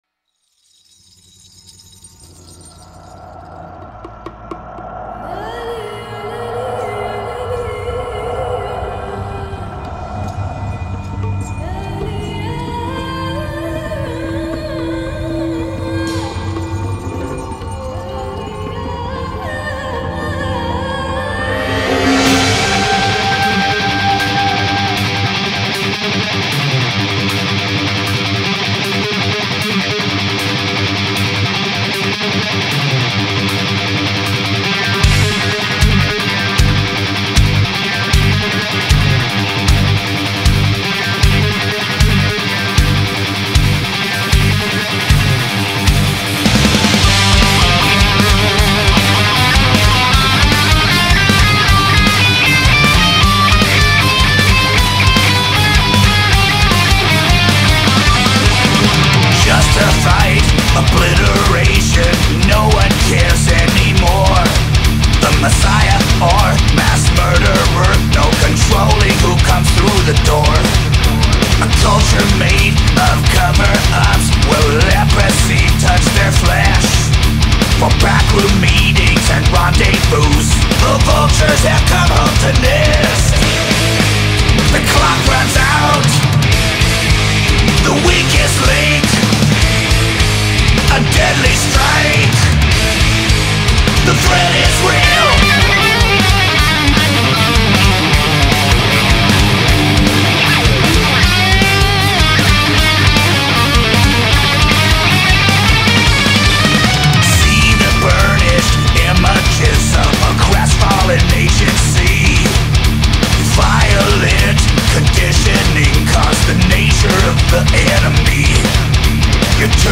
Thrash Metal, Heavy Metal